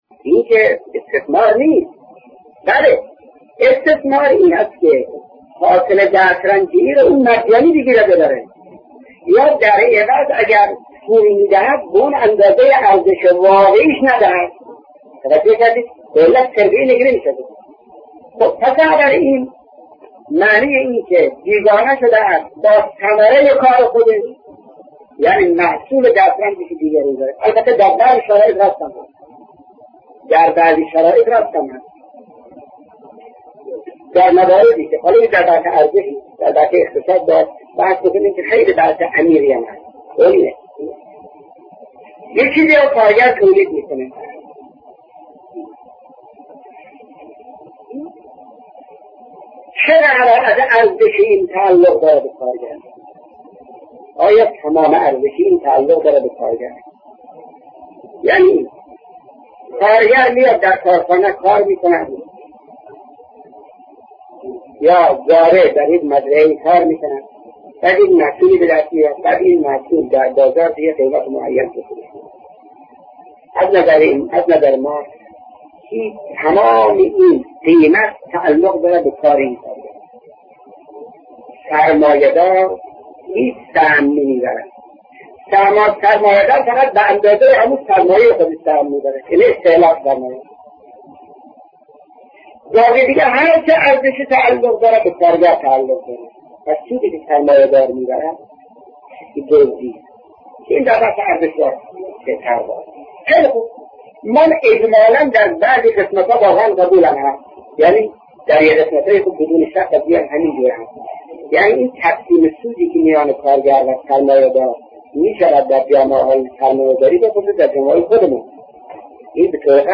سخنرانی شهید مرتضی مطهری(ره)- با موضوع بیگانگی انسان از خدا- بخش‌سوم